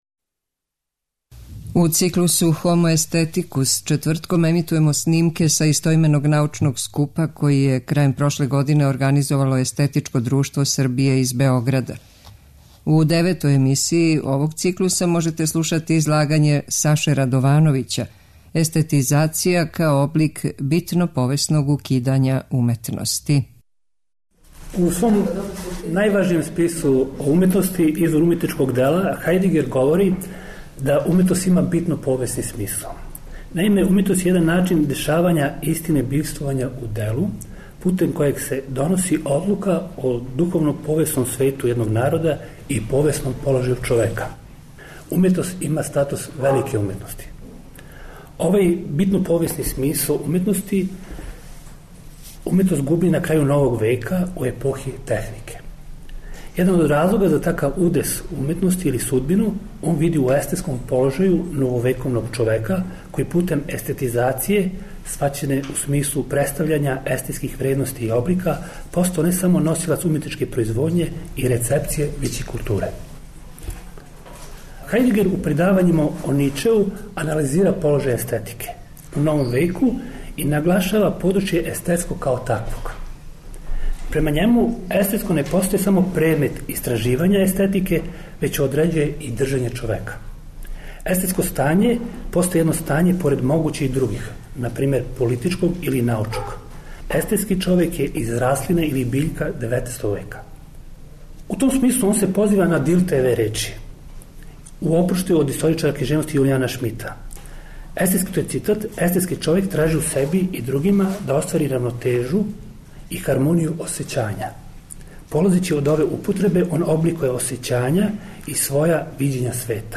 У циклусу HOMO AESTHETICUS четвртком ћемо емитовати снимке са истоименог научног скупа који је, у организацији Естетичког друштва Србије, одржан 22. и 23. децембра у Заводу за проучавање културног развитка у Београду.
Научни скупoви